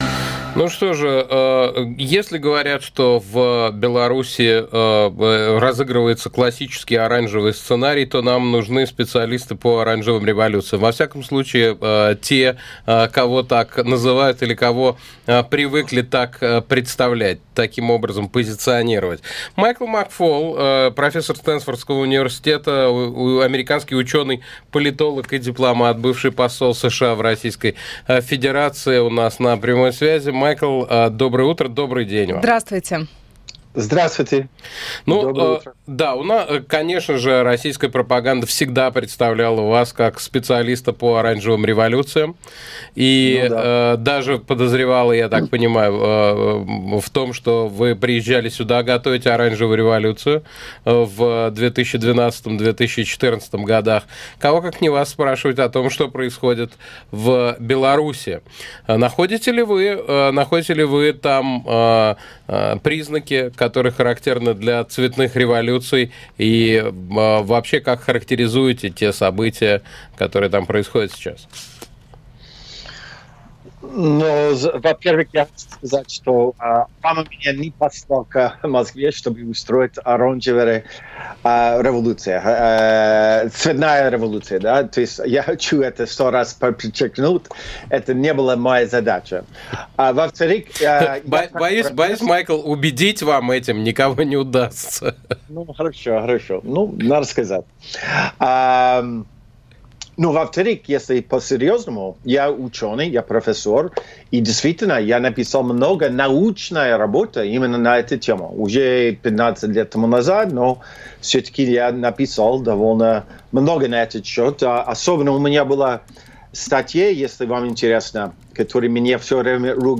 Майкл Макфол, профессор Стэнфордского университета, американский ученый, политолог и дипломат, бывший посол США в России у нас на прямой связи.